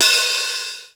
HiHatOp.wav